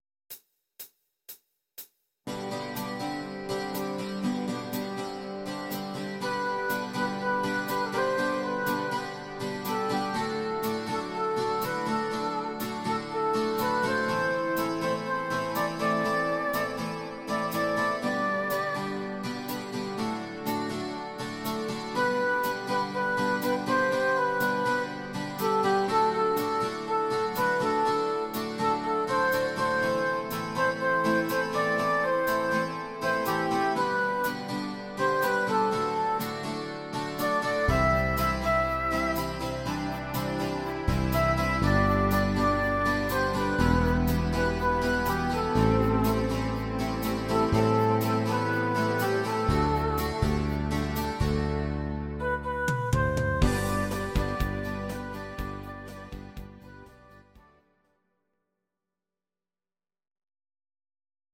Audio Recordings based on Midi-files
German, Duets, 2010s